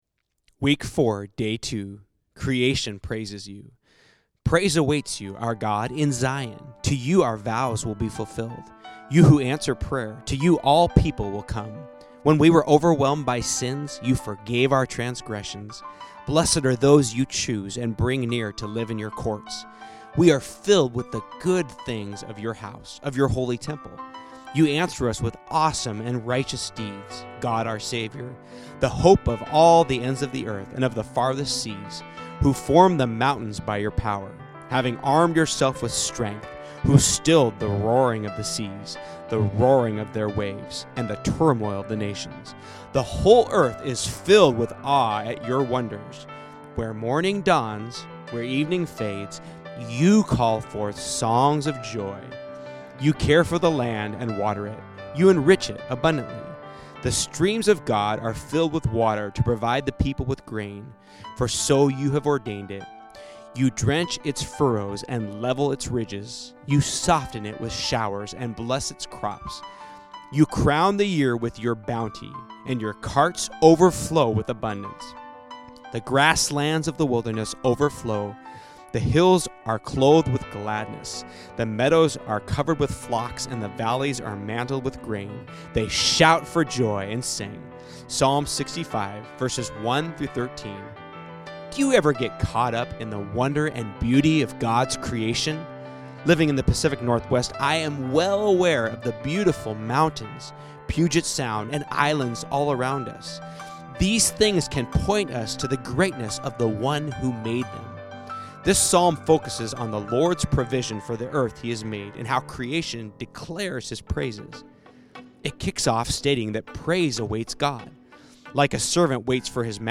Get the Podcast (audio book version) with the piano music in the background at apple music, spotify, etc. or download at CDbaby.